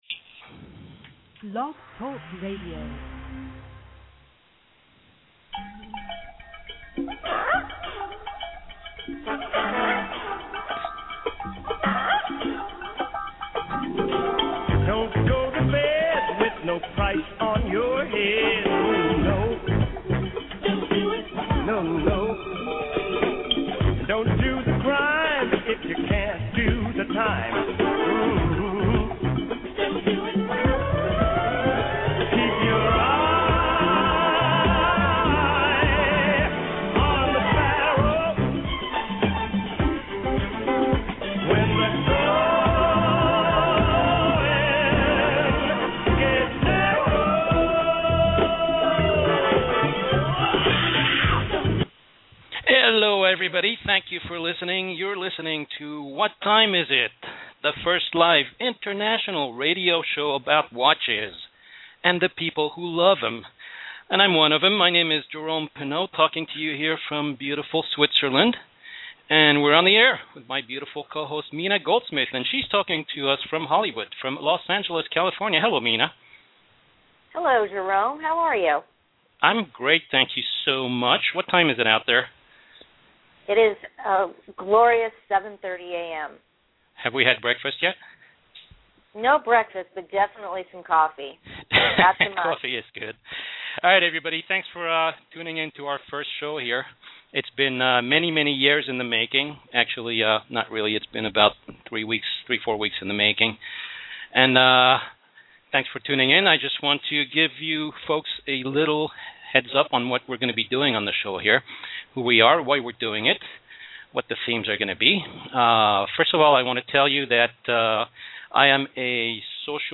About the radio show
“What Time Is It?” is the first live international web radio show about watches and the people who love them!
You can also call in and participate via phone or Skype.